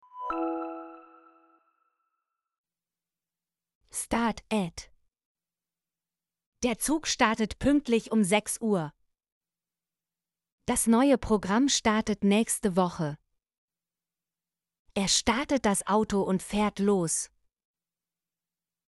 startet - Example Sentences & Pronunciation, German Frequency List